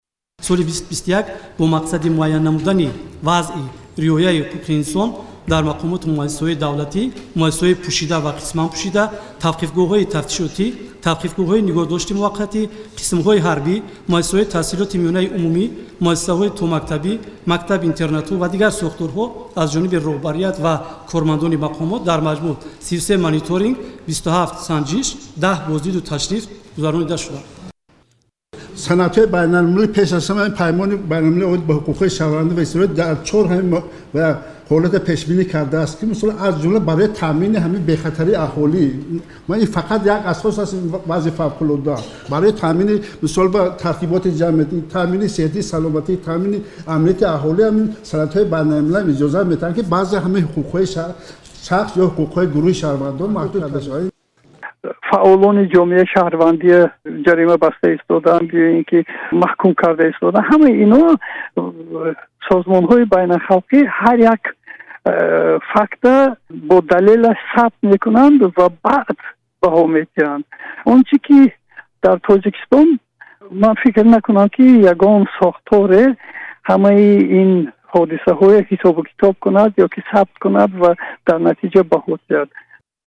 таҳлилгари тоҷик